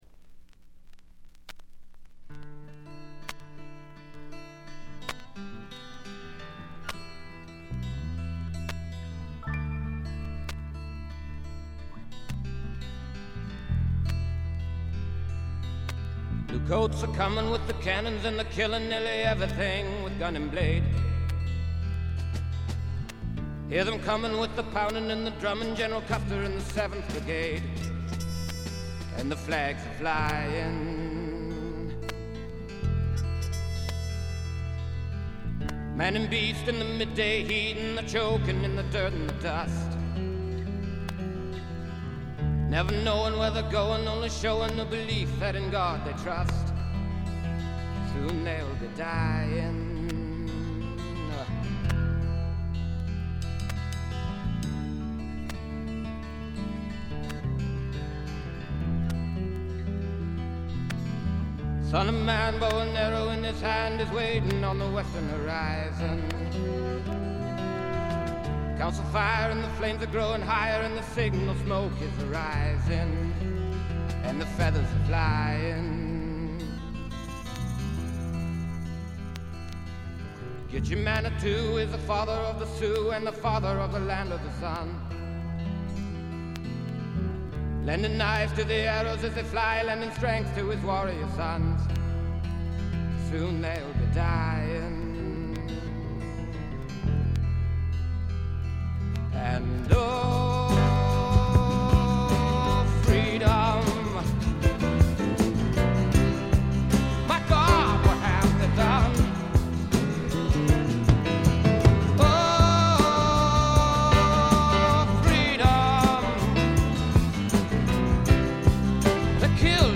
A面冒頭部周回ノイズ。これ以外はわずかなチリプチ程度。
試聴曲は現品からの取り込み音源です。